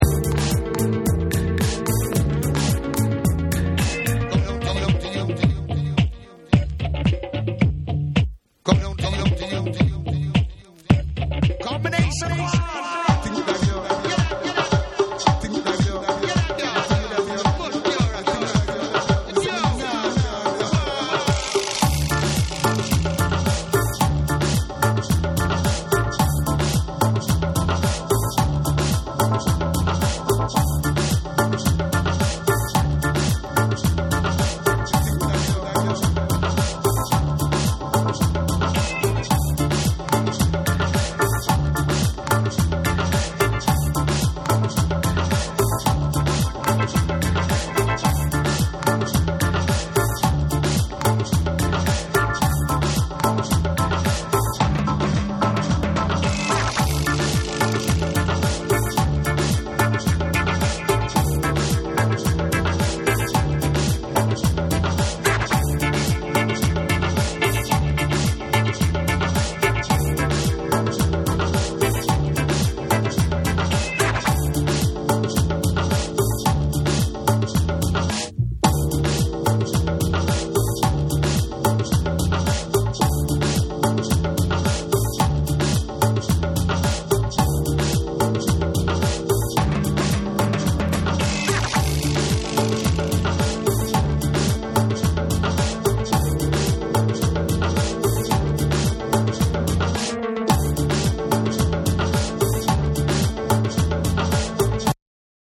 ミステリアスなシンセやヴォイスサンプルが、彼等らしいジャズの要素も含んだ重厚なダブ・サウンド上で展開した1。
BREAKBEATS / JAPANESE / NEW RELEASE